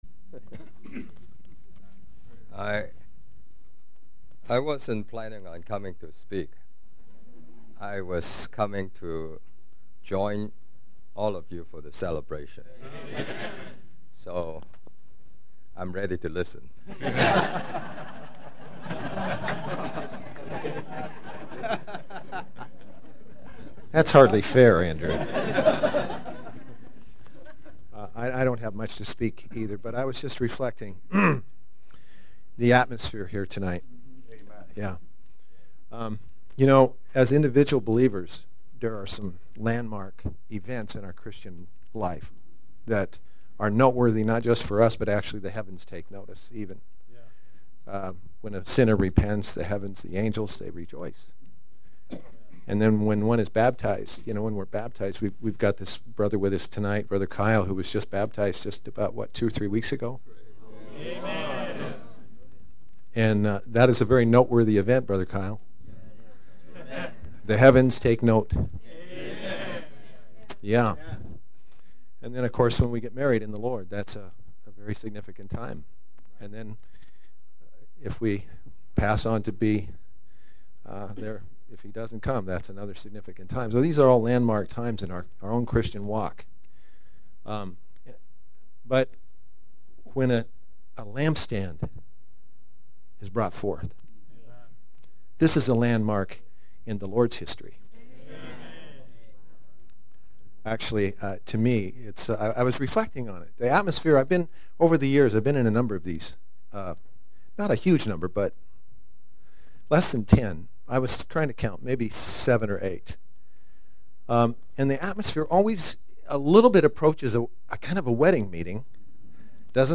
Inaugural Lord’s Table
The first Lord’s Table of the church in Lake Forest was attended by many visiting saints on September 19, 2010. Coworkers from throughout southern California attended and some spoke short or longer words to the church here.
2-Coworkers-sharing.mp3